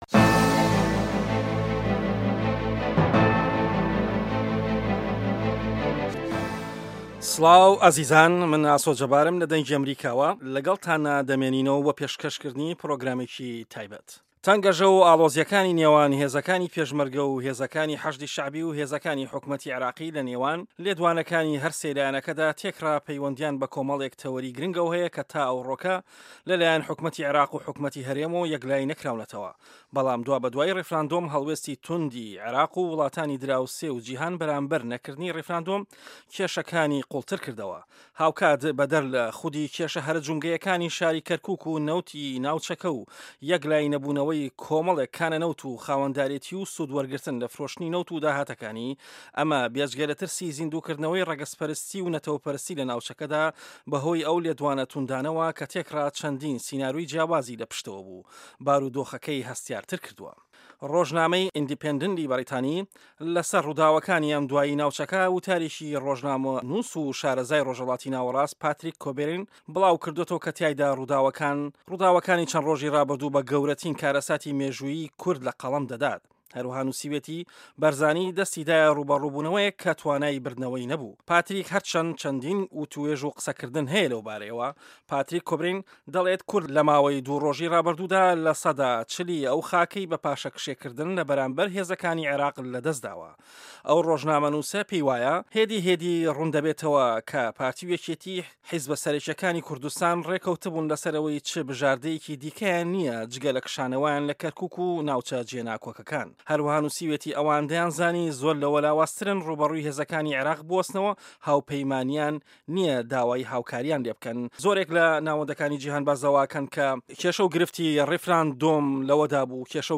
دەقی مێزگردەکە